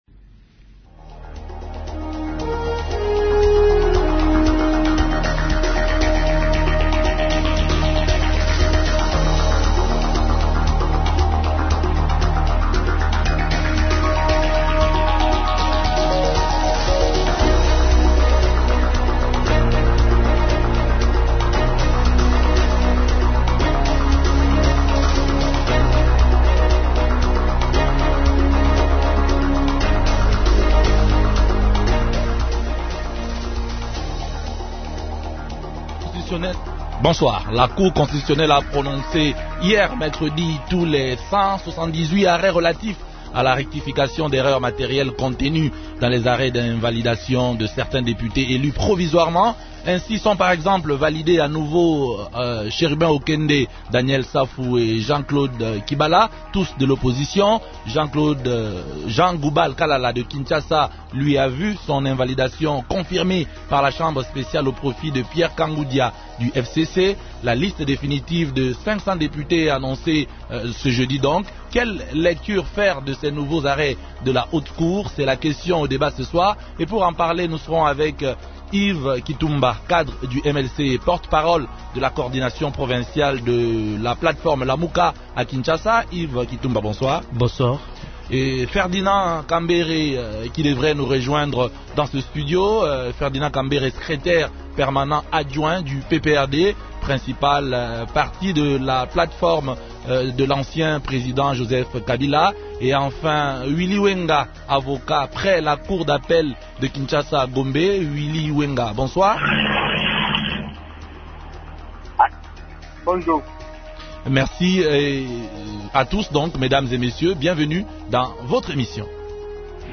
Quelle lecture faire de ces nouveaux arrêts de la haute cour ? Invités